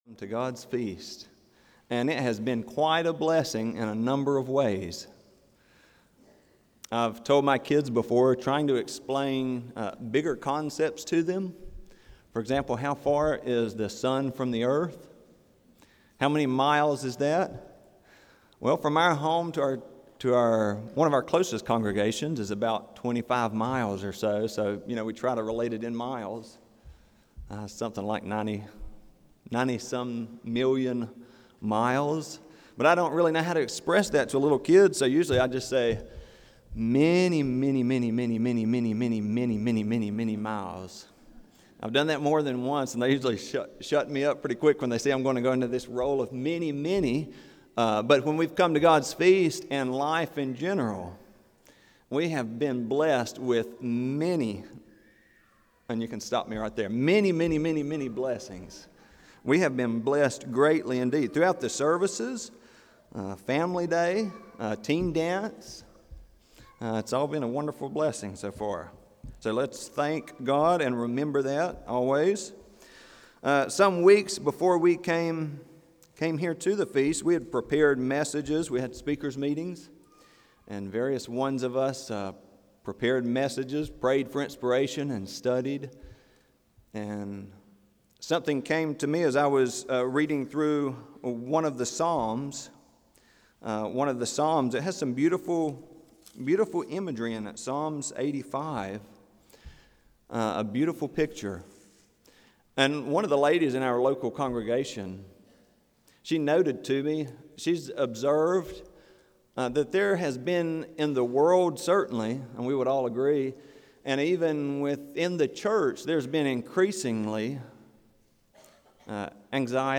This sermon was given at the Jekyll Island, Georgia 2023 Feast site.